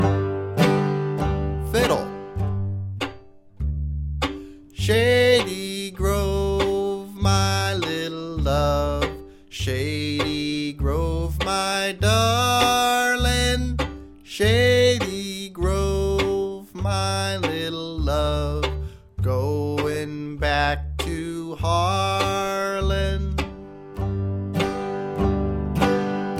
Rhythm: Mandolin